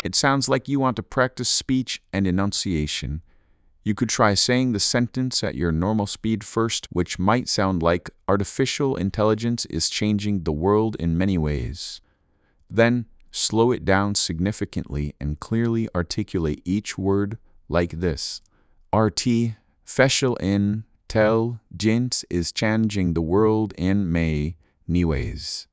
rhythm1_Babble_1.wav